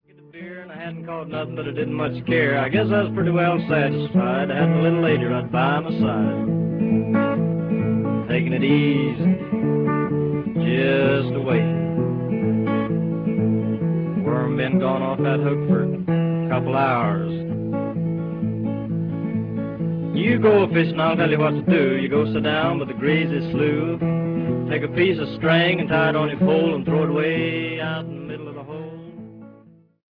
Recorded in New York, New York between 1944 and 1947.